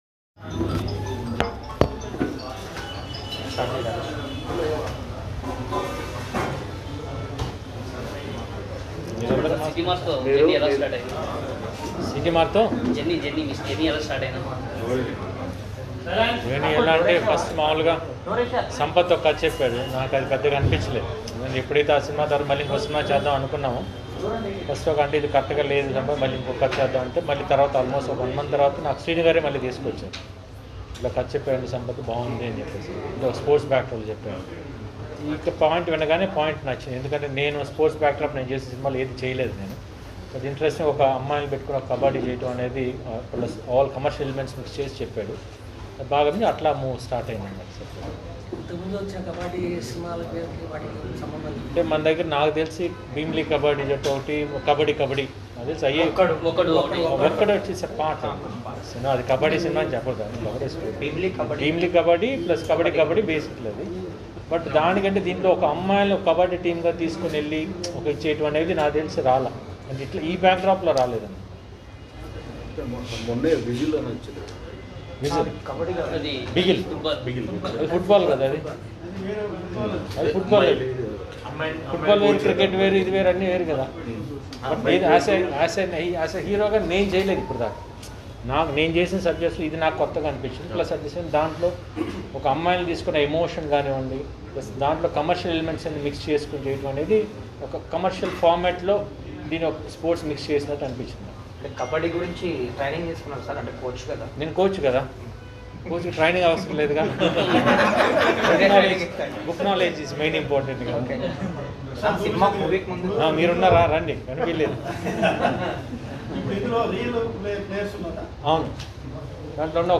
Gopichand Stills & Audio From Seetimaarr Movie Interview - Social News XYZ